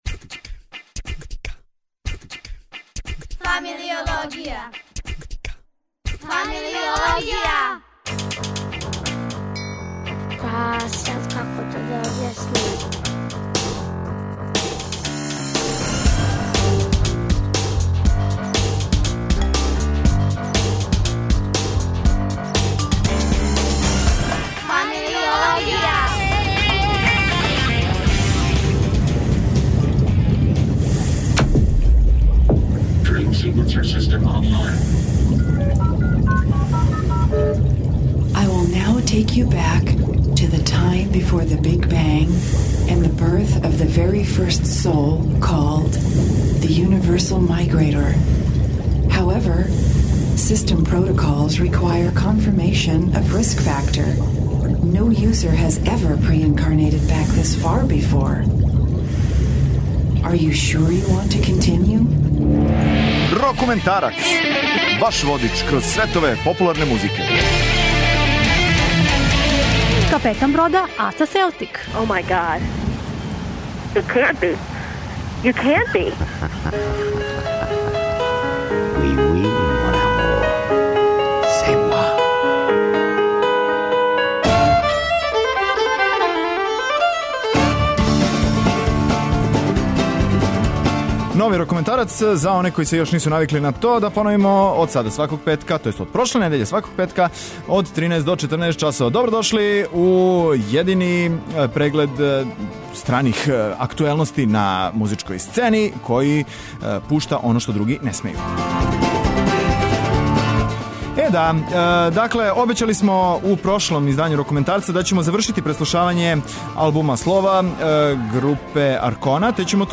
У емисији ћемо слушати неке од највећих хитова те групе.